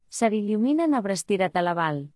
Melorin is renowned for its melodic and song-like quality, with a rhythmic cadence that flows effortlessly.
Example sentences